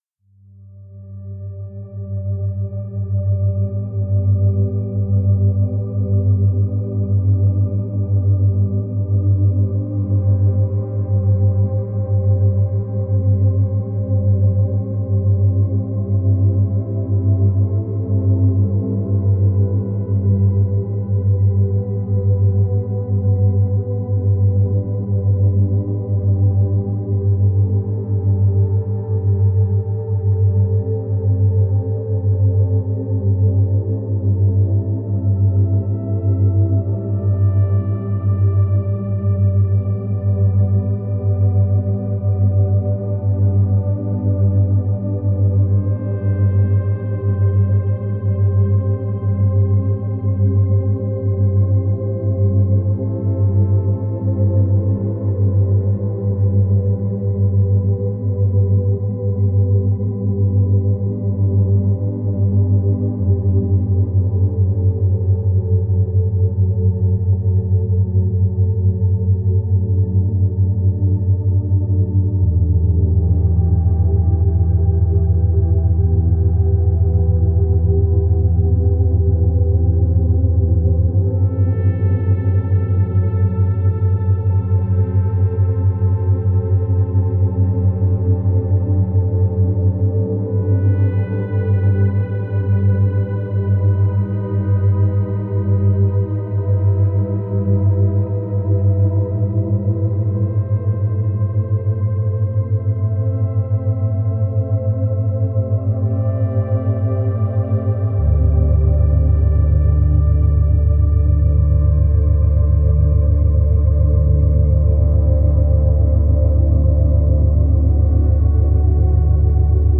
97Hz – 104Hz
در این فصل قرار هست با ضرباهنگ‌های دوگانه آشنا بشیم.
Binaural Beats
97Hz - 104Hz.mp3